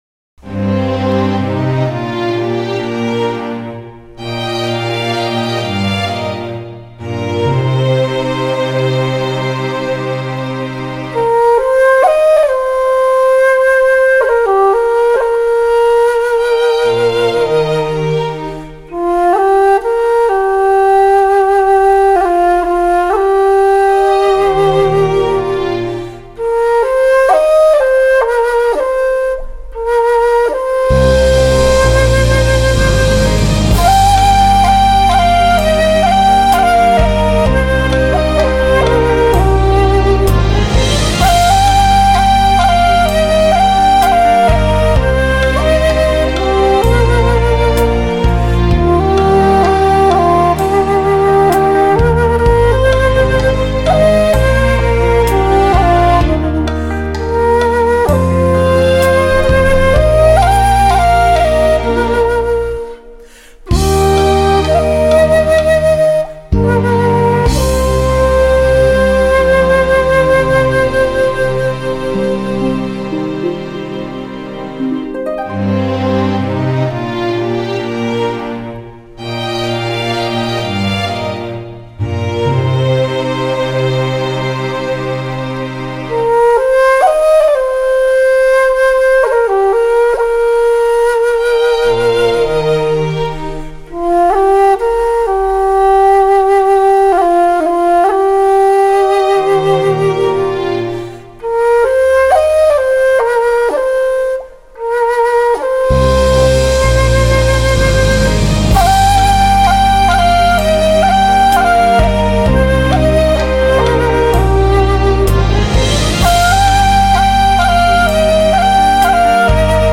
乐器： 箫